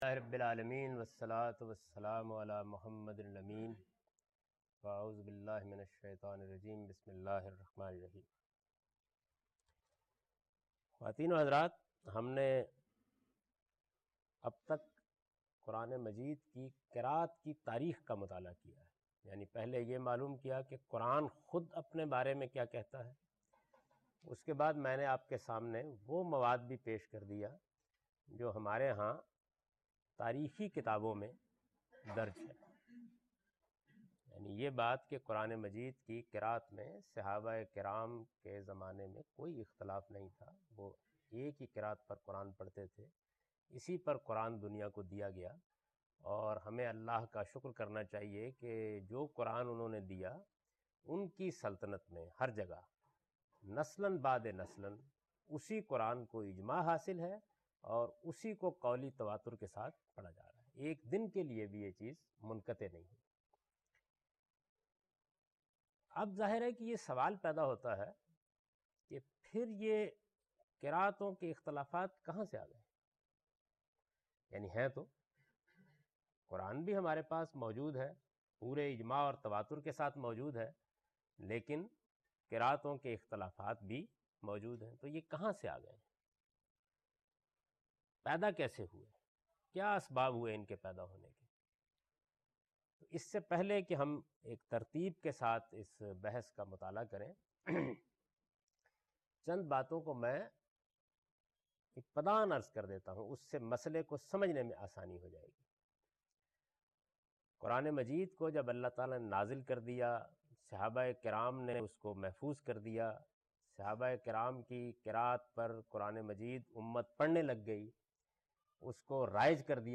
A comprehensive course on Islam, wherein Javed Ahmad Ghamidi teaches his book ‘Meezan’.
In this lecture he teaches the variant readings of Quran.